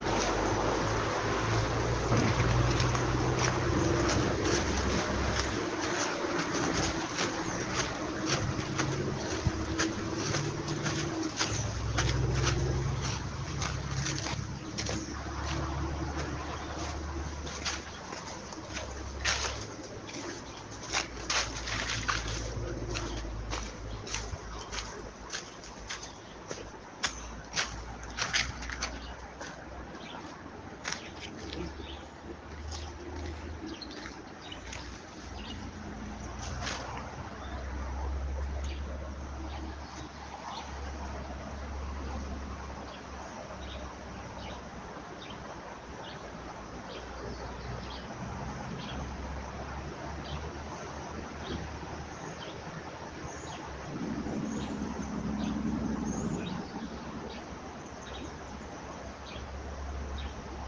Río Barranca-Ángeles Sur de San Ramón ALAJUELA